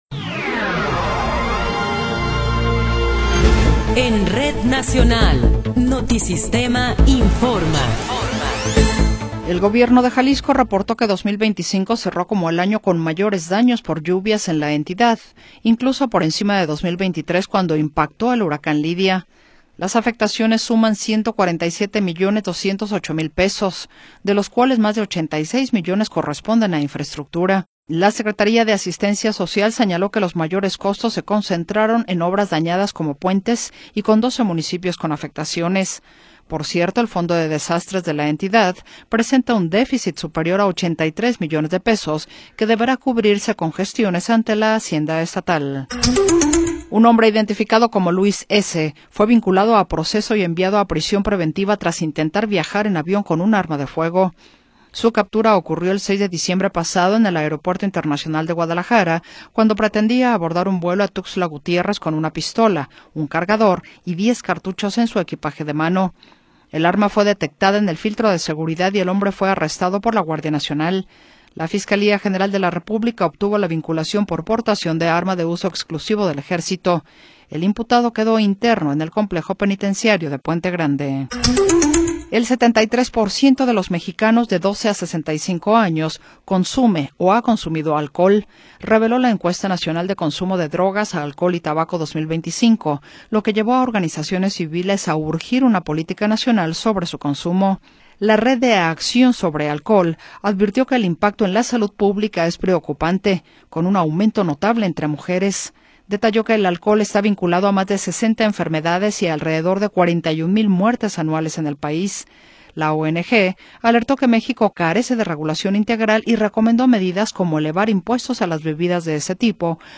Noticiero 17 hrs. – 17 de Enero de 2026
Resumen informativo Notisistema, la mejor y más completa información cada hora en la hora.